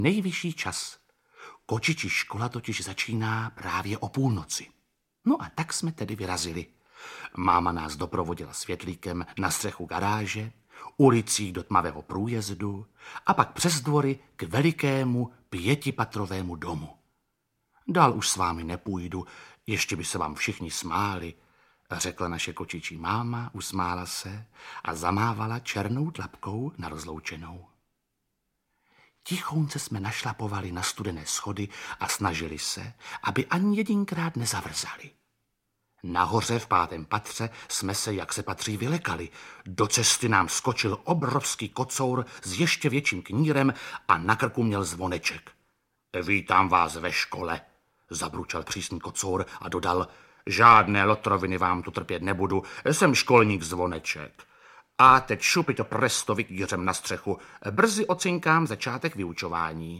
Audiobook
Read: Jiří Lábus